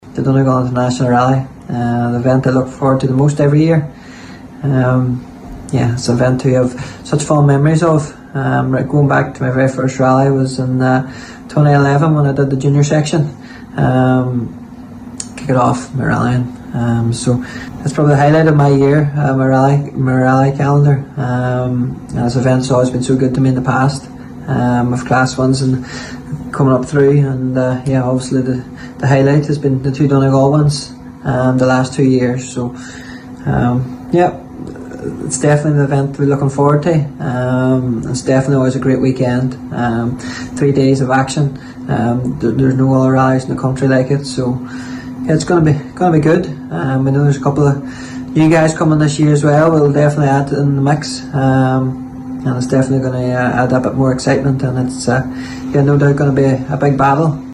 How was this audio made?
Donegal International Rally Launch night interviews